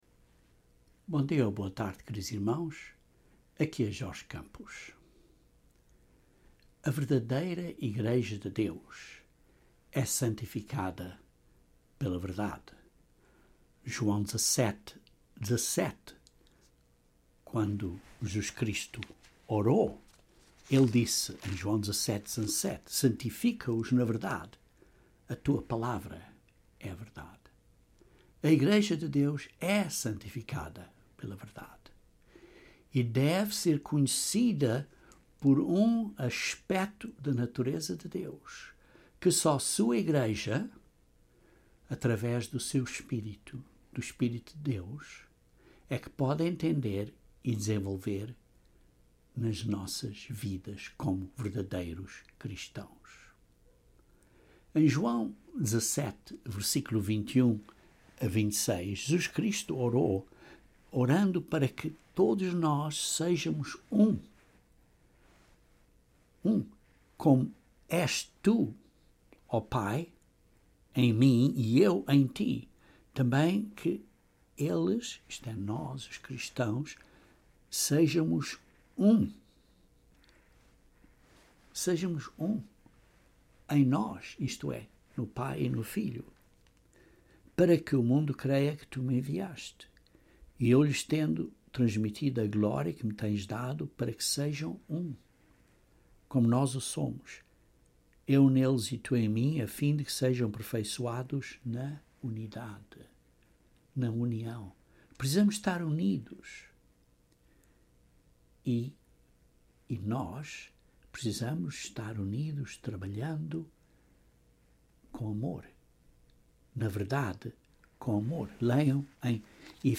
Amor ágape é definido somente pela Bíblia e pelo Espírito de Deus. Este sermão constroi a definição de ágape diretamente da Bíblia para você entender o que Deus deseja que desenvolva.